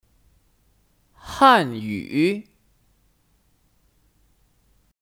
汉语 (Hànyǔ 汉语)